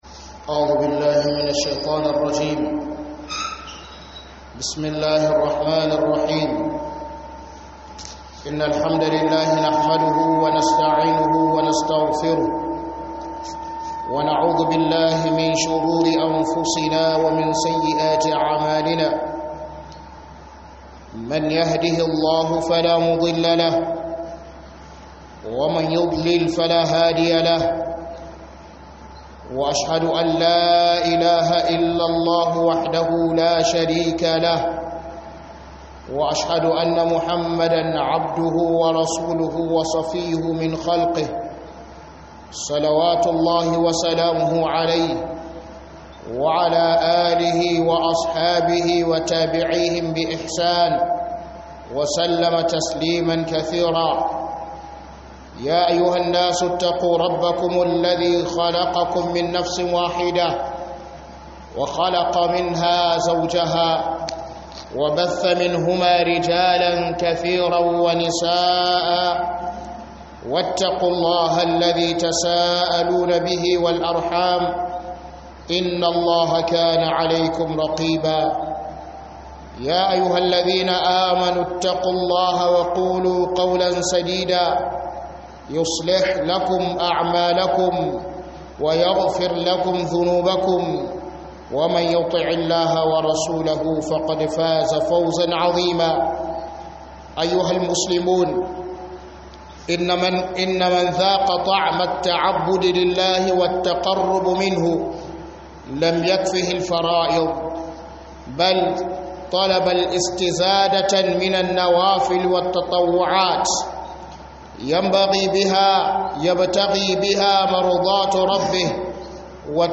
HUƊUBAR JUMA'A KAN DAGEWA KAN NAFILFILIN IBADA.